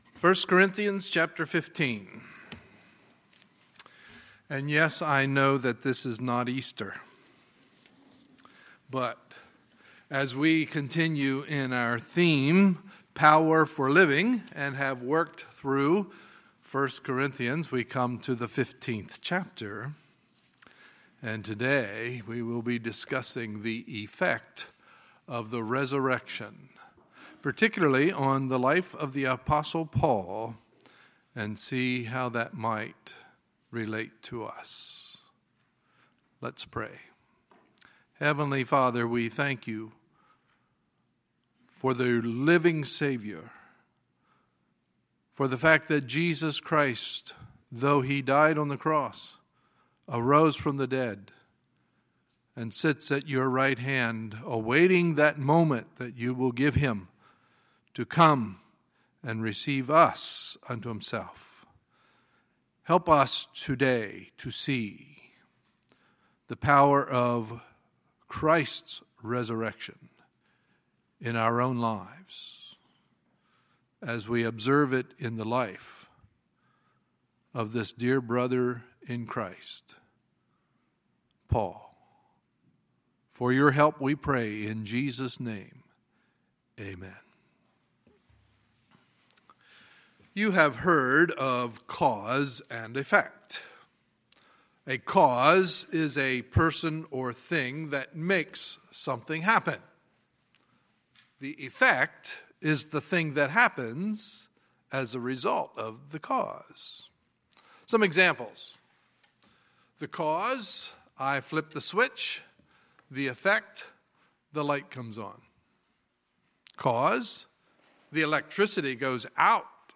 Sunday, July 9, 2017 – Sunday Morning Service